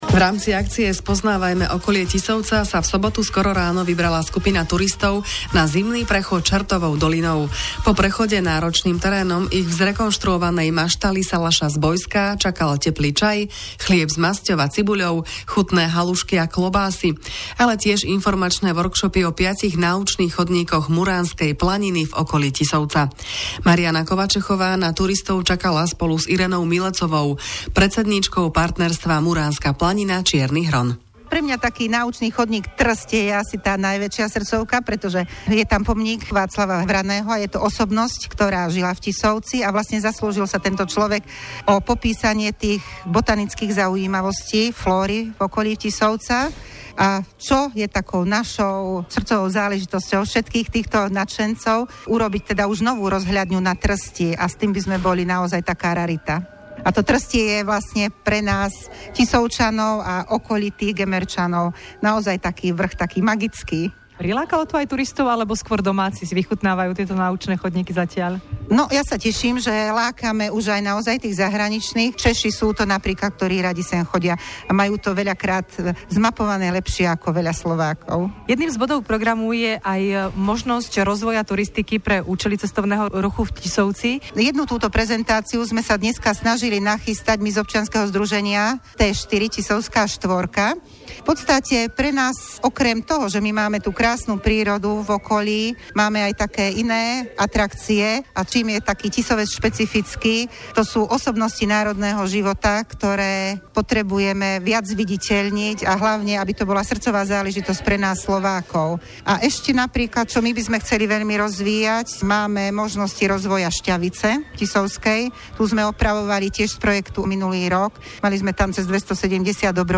Tá vyspovedala zopár účastníkov akcie a vytvorila o nej pár minútový vstup, odvysielaný na Rádiu Regina 2. marca o 6.40 hod.